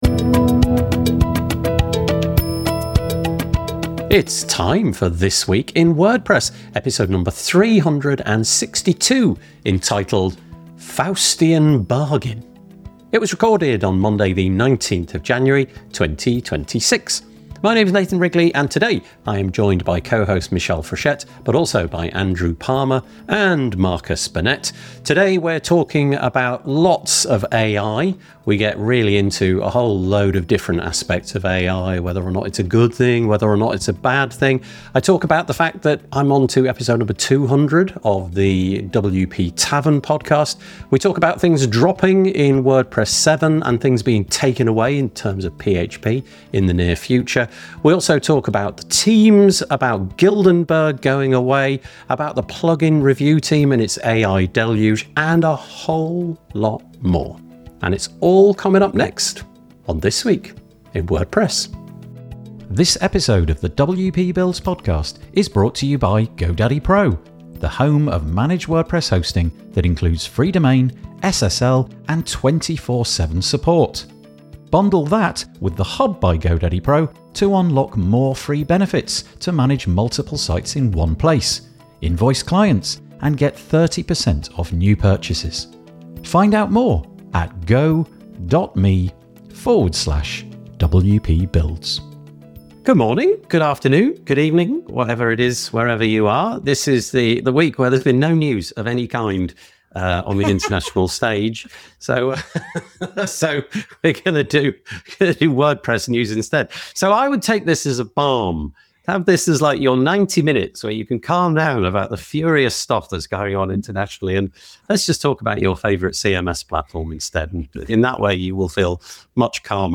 This episode covers the latest developments in WordPress, including discussions on the impact and future of AI in the ecosystem, details about the upcoming WordPress 7.0 release, the drop of older PHP support, and significant changes to plugin submissions due to a surge in AI-generated plugins. The panel also touches on new community roles, shifts in event structures, and notable news from both WooCommerce and Guildenberg, while reflecting on the broader trends shaping the WordPress landscape in 2026.